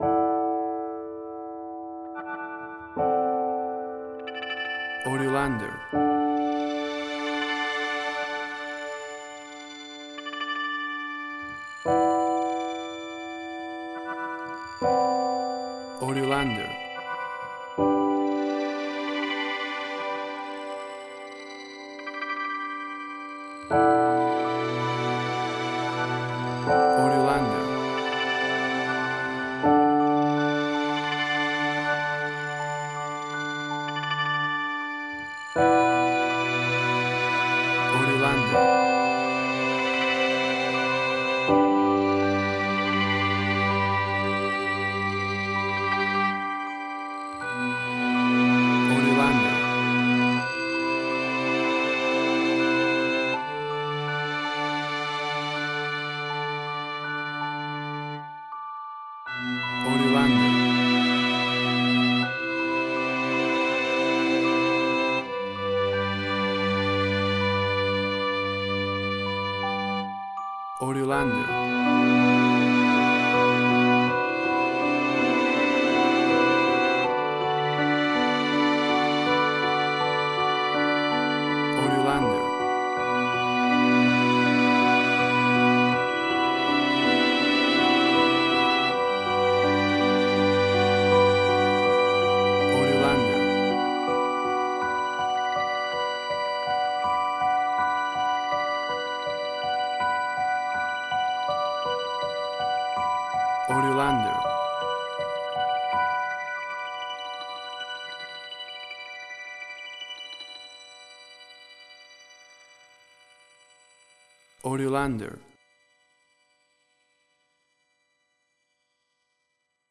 Indie Quirky.
Tempo (BPM): 81